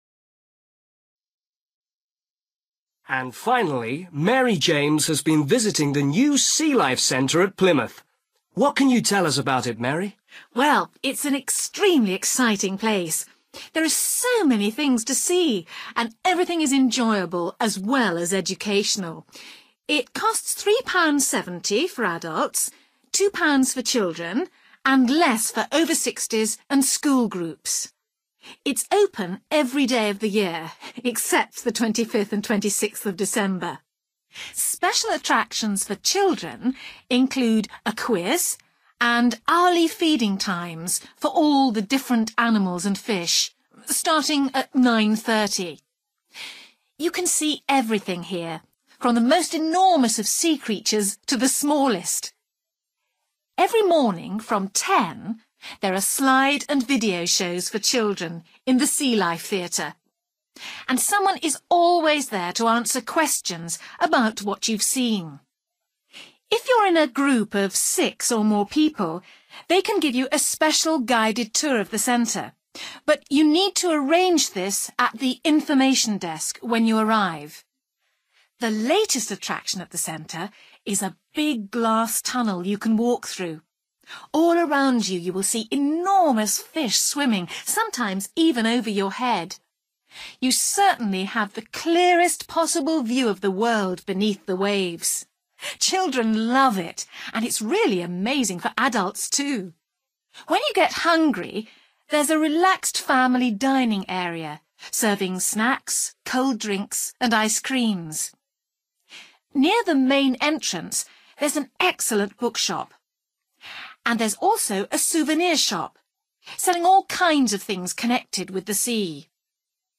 You will hear a radio announcer talking about Plymouth Sea Life Centre.